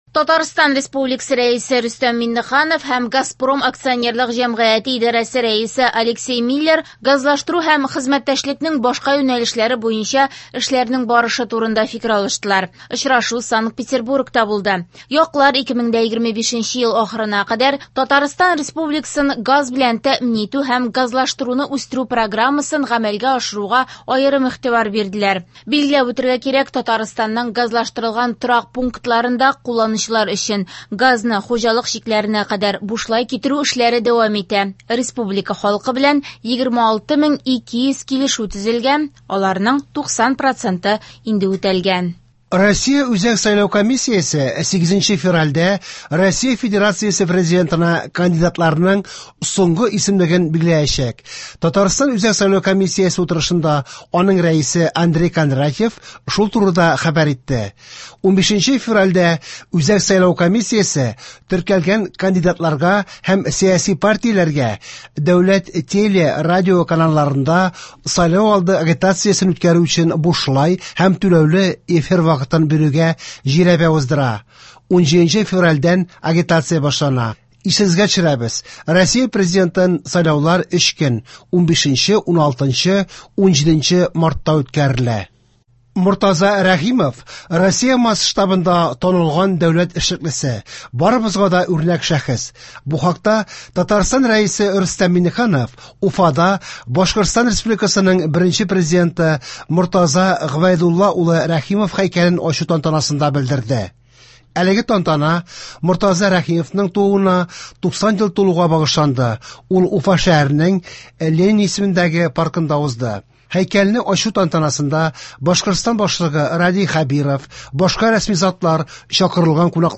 Яңалыклар (8.02.24)